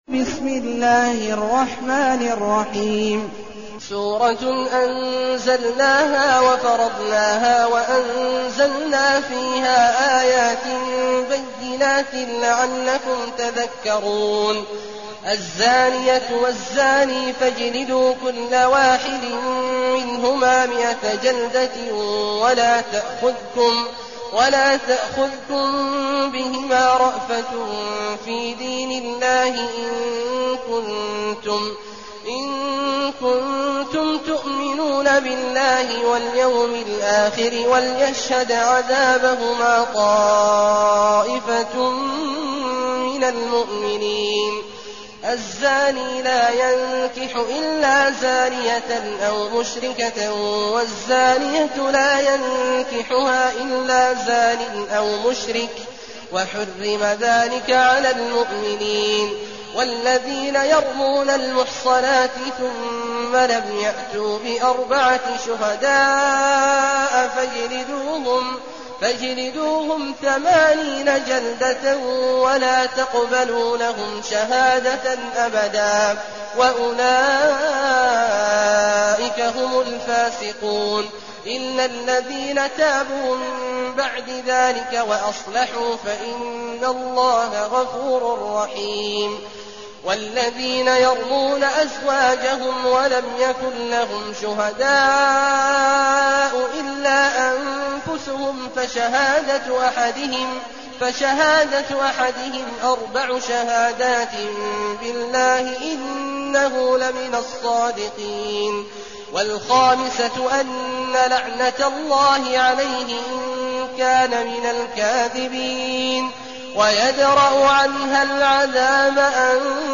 المكان: المسجد النبوي الشيخ: فضيلة الشيخ عبدالله الجهني فضيلة الشيخ عبدالله الجهني النور